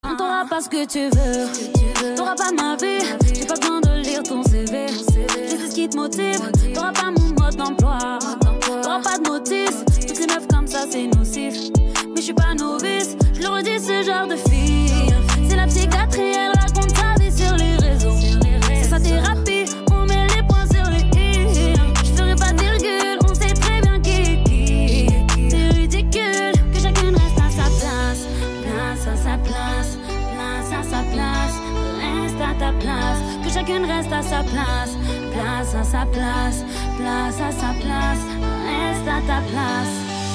Rap / Hip Hop